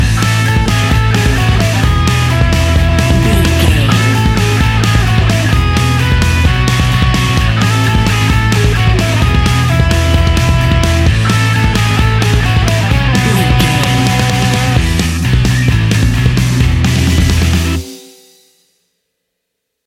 Ionian/Major
energetic
driving
heavy
aggressive
electric guitar
bass guitar
drums
hard rock
distortion
punk metal
instrumentals
distorted guitars
hammond organ